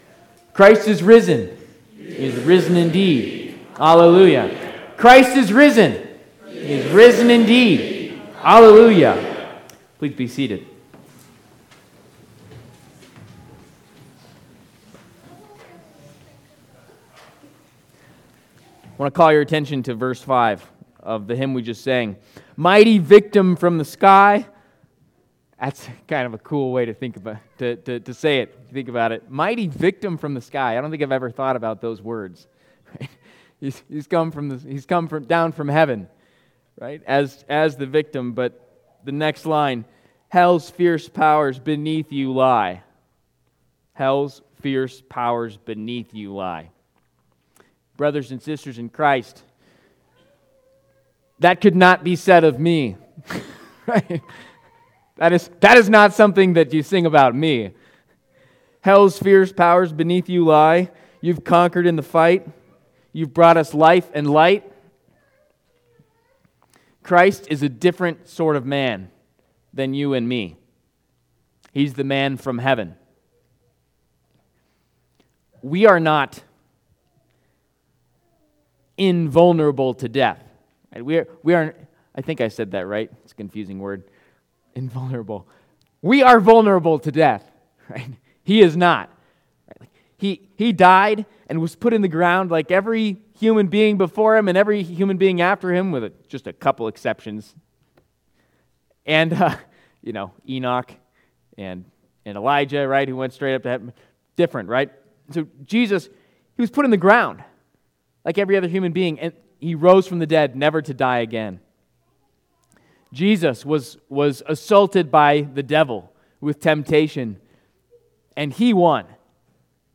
Sermon for the fifth Sunday of Easter
Trinity Lutheran Church, Greeley, Colorado I Am the Vine Apr 28 2024 | 00:17:42 1x 00:00 / 00:17:42 Subscribe Share RSS Feed Share Link Embed